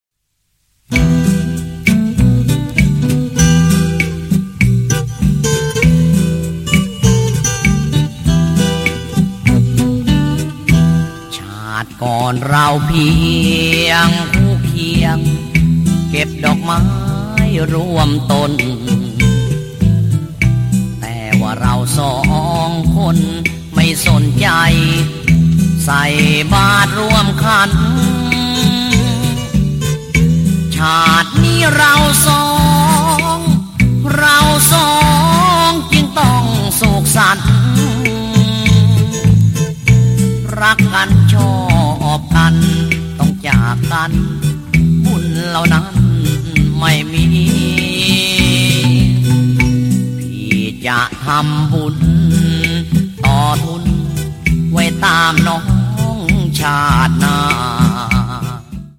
CD曲はオリジナル曲の歌声を、新装ギター伴奏にハメ込んだものでした。
それなりに裏街風情が漂っていて…